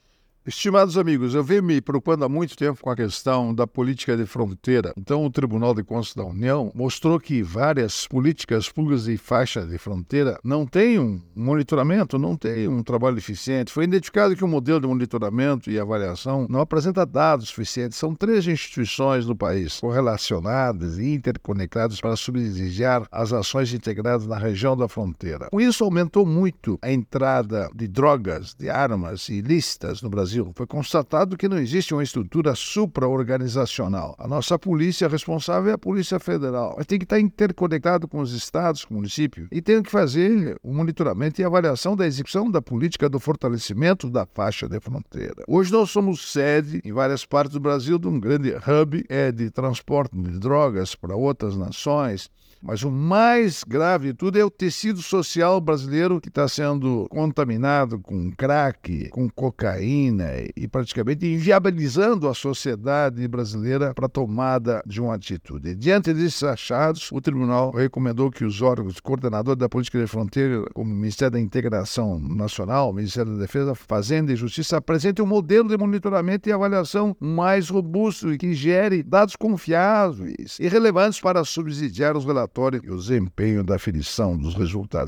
É o assunto do comentário desta quinta-feira (22/08/24) do ministro Augusto Nardes (TCU), especialmente para OgazeteitO.